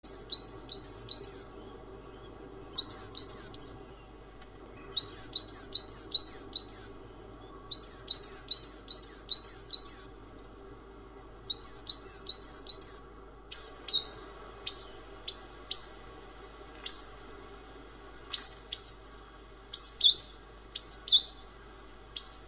Klingelton Vogelstimmen
Kategorien Tierstimmen